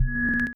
Slowmo.wav